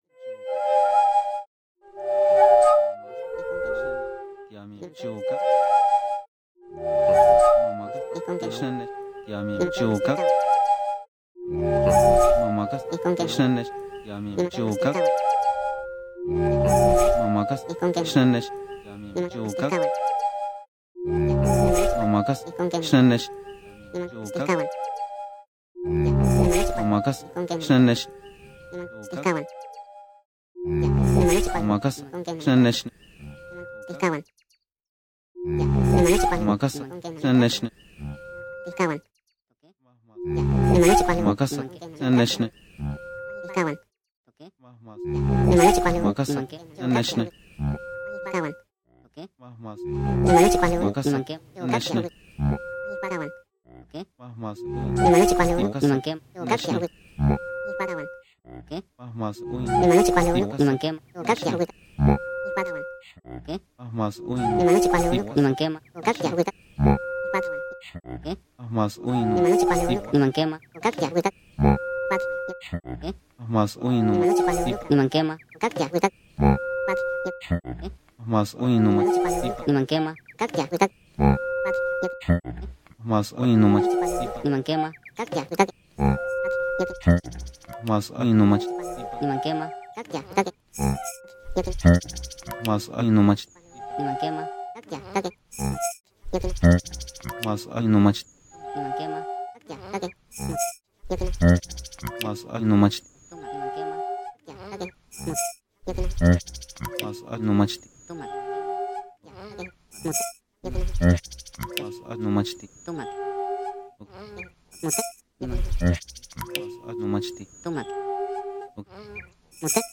Algorithm running on audio samples.